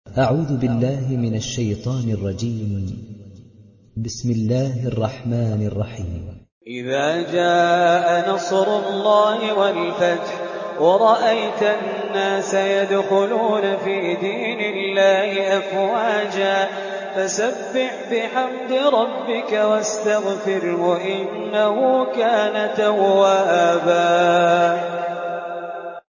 Riwayat Hafs